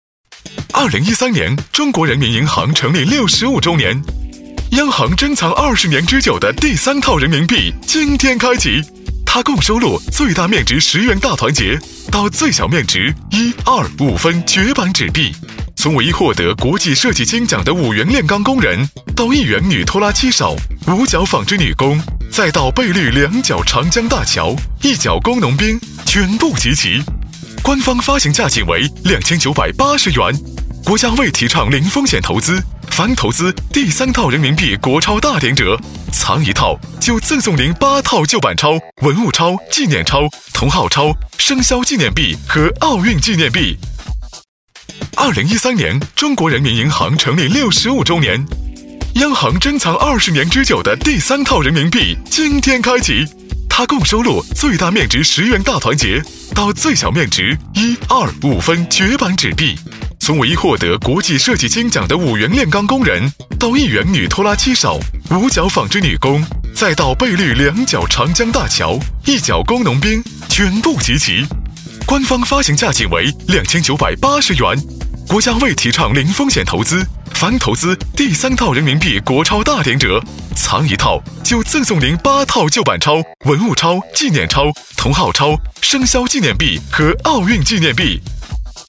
• 男S355 国语 男声 广告-第三套人民币国钞大典-电台广播-动感 厚重 大气浑厚磁性|沉稳|科技感|积极向上|时尚活力